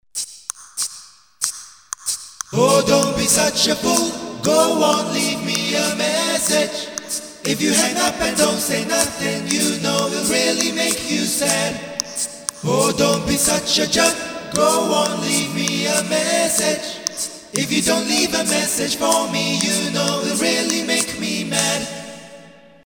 a great Cuban a cappella group
Consists of 6 voices - three parts, plus vocal percussion.